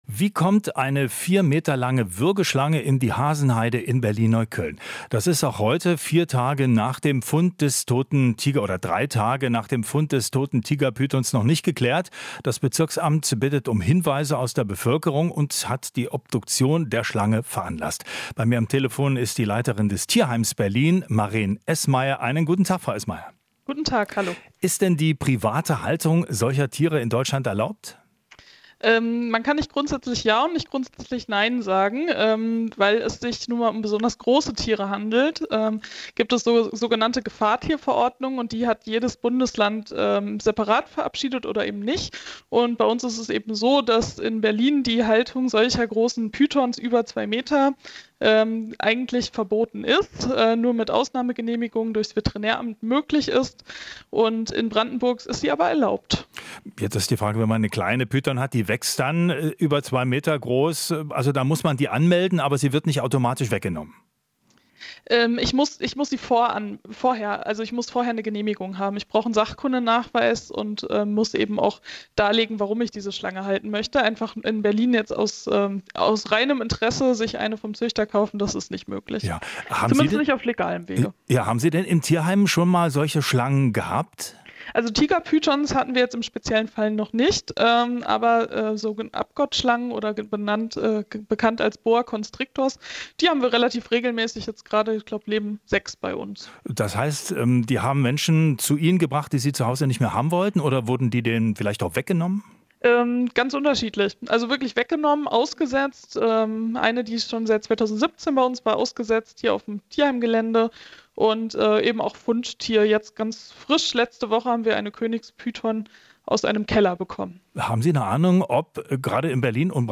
Interview - Würgeschlange in Berliner Hasenheide gefunden